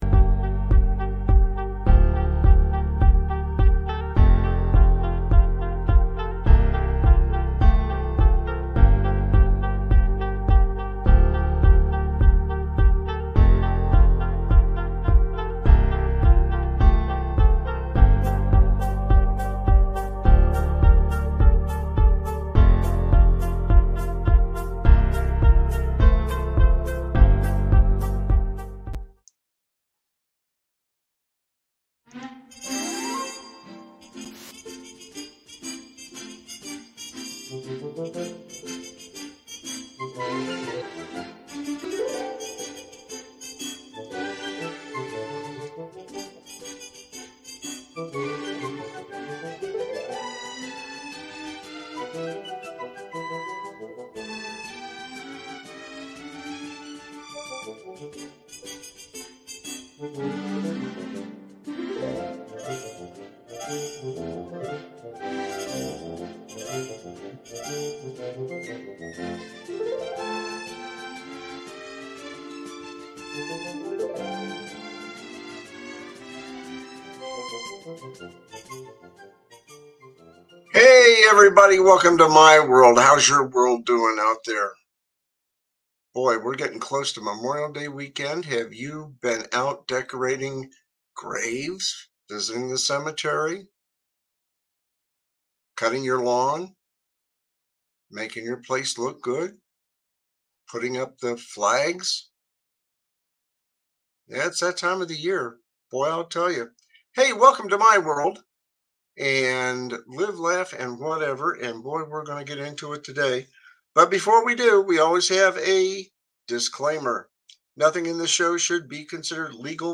My WorldLive, Laff, Whatever is a satirical talk show that tackles the absurdities of life with ahealthy dose of humor.
No topic is off-limits, and his guests, arotating cast of comedians, commentators, and everyday folks, add their own uniqueperspectives to the mix.